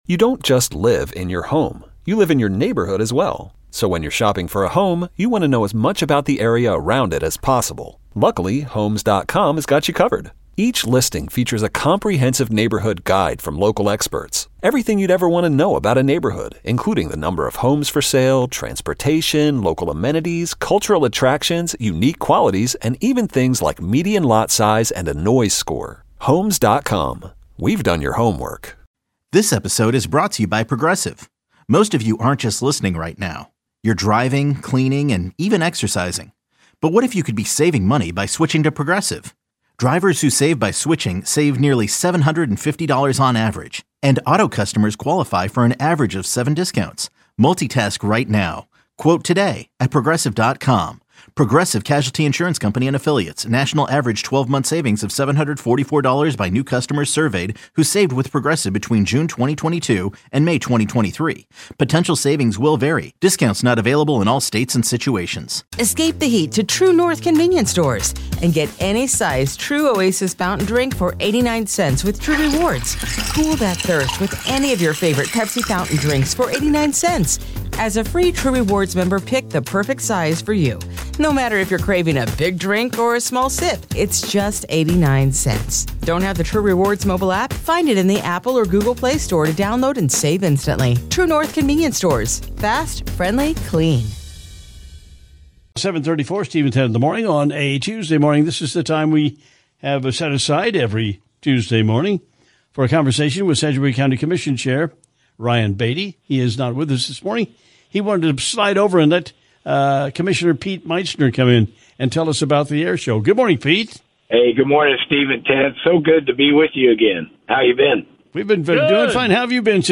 But today we get a chance to talk once again with Commissioner Pete Meitzner with a report after his trip to represent the county at the show in France.